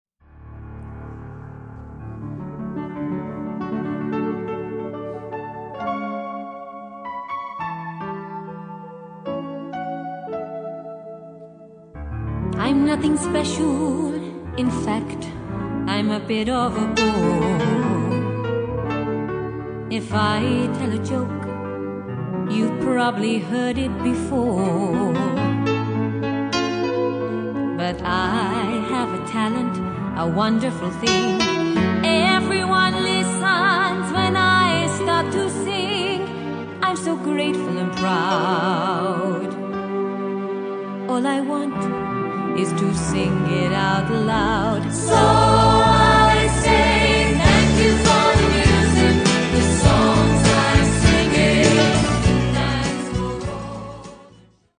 a 5 piece live band with 5 part harmonies